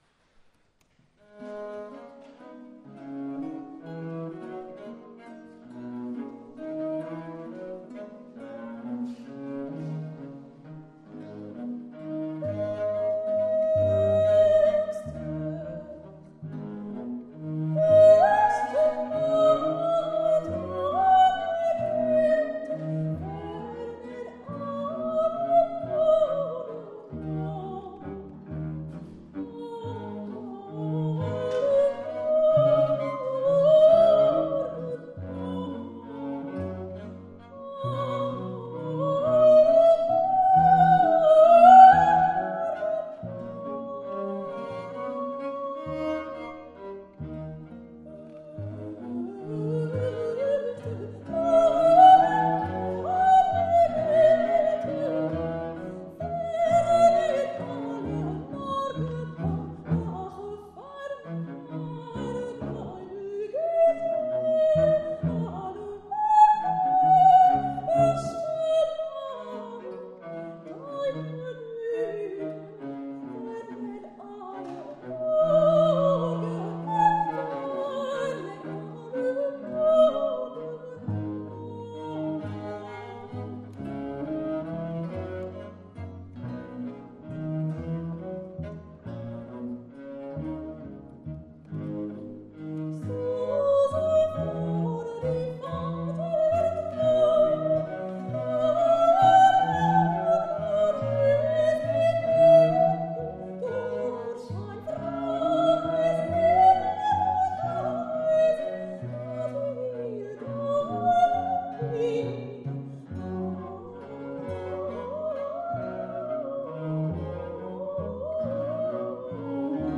La musique baroque vivante !
Enregistré à l'église d'Arberats (64120) le 24 Juillet 2012